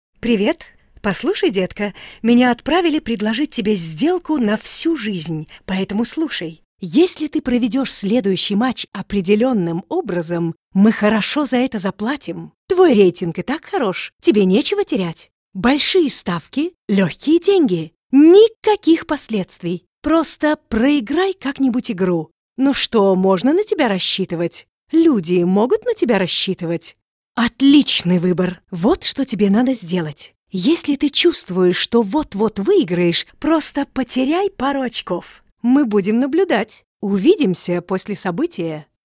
warm and deep for narrations, sexy and fun for commercials, smooth and professional for presentations
Sprechprobe: Sonstiges (Muttersprache):